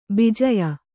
♪ bijaya